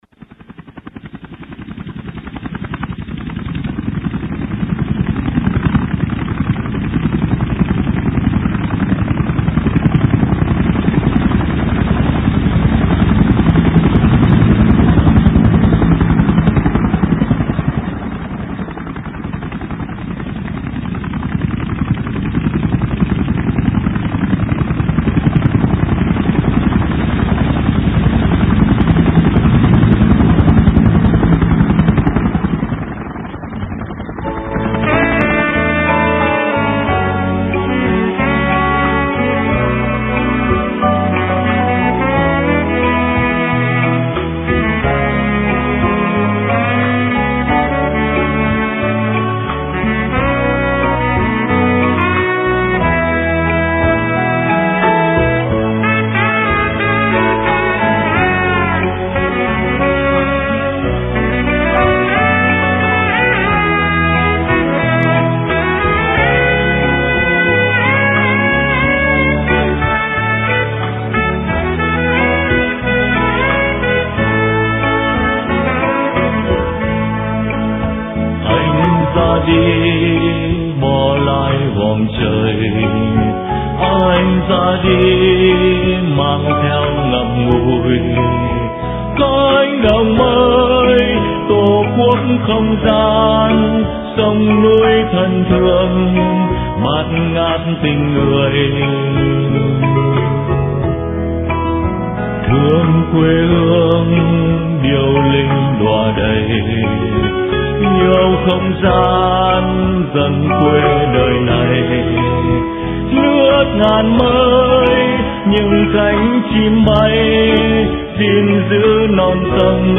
Từ Cánh Đồng Mây - Phỏng vấn